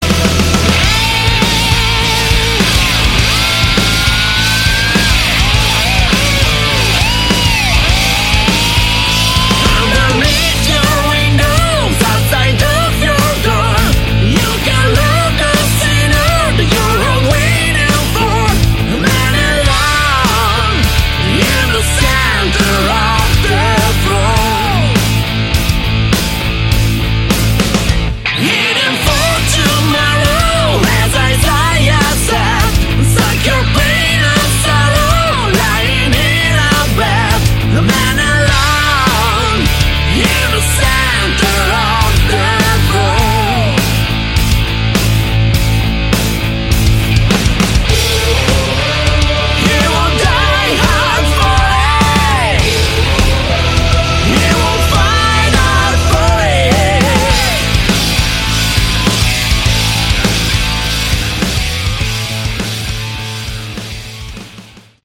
Category: Melodic Hard Rock
vocals
lead, rhythm, and acoustic guitars, keyboards
bass
drums